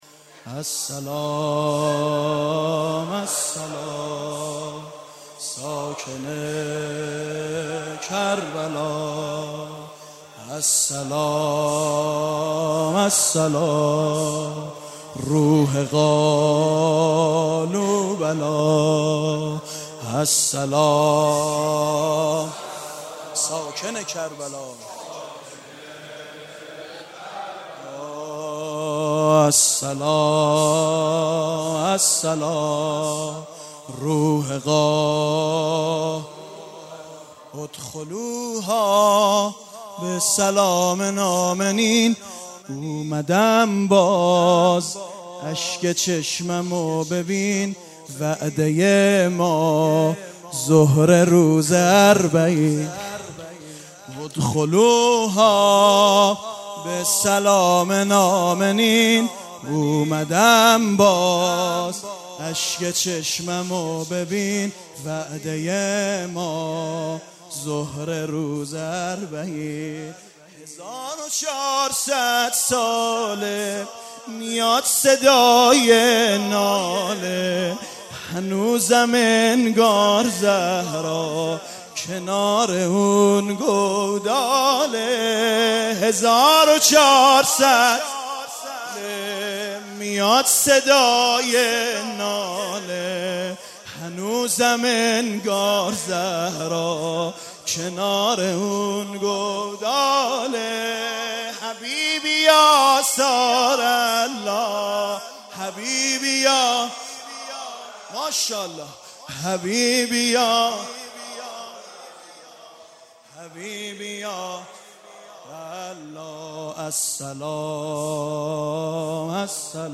از بانک مداحی و روضه
شب نهم محرم